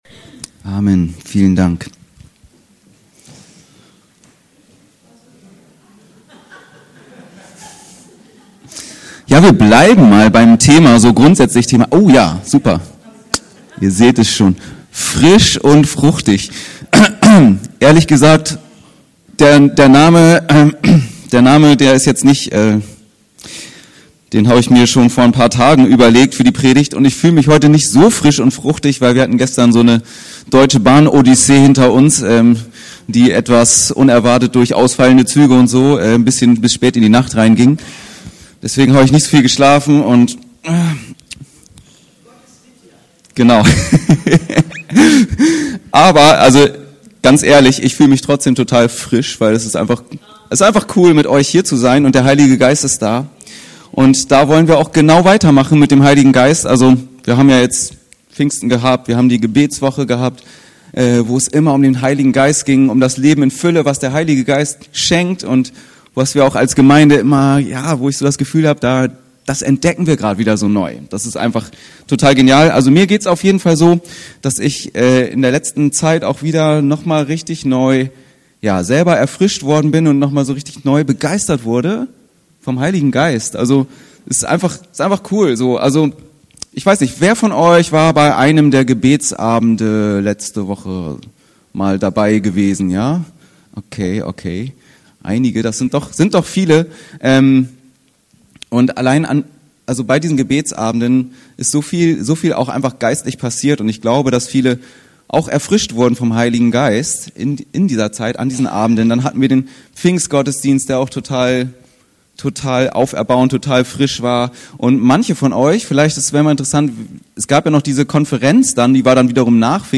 Frisch und fruchtig – Was der Geist in uns wachsen lässt (Gal 5,22 f.) ~ Anskar-Kirche Hamburg- Predigten Podcast